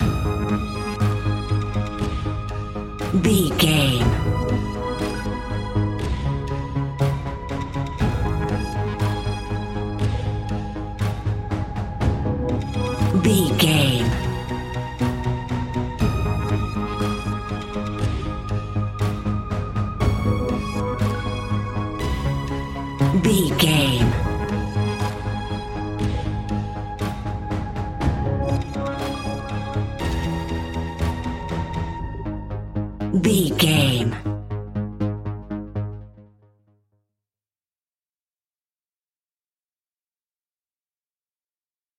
In-crescendo
Aeolian/Minor
scary
ominous
dark
haunting
eerie
creepy
instrumentals
horror music
Horror Pads
horror piano
Horror Synths